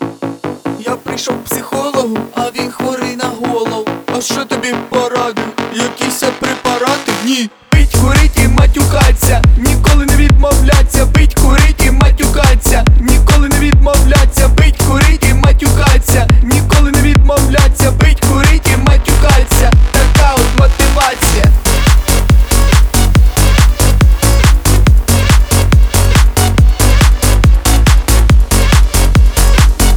Жанр: Танцевальные / Русские
# Dance